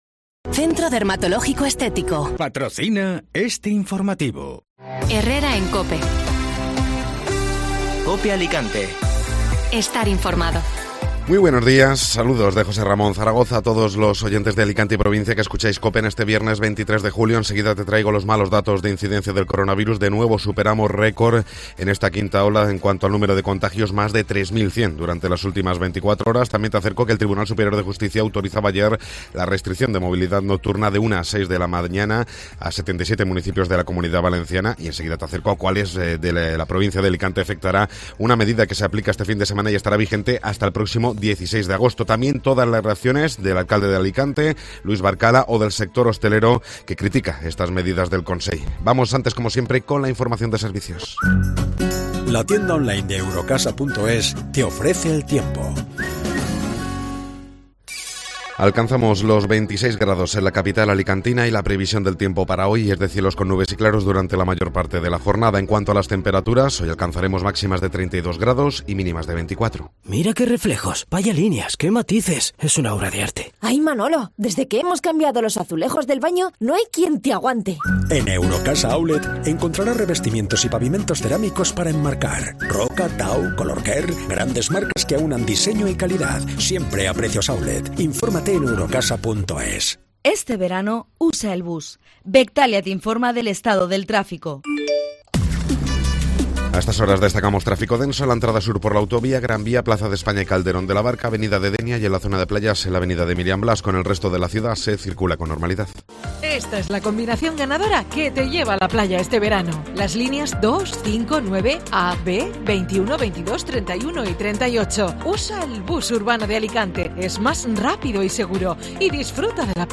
Informativo Matinal (Viernes 23 de Julio)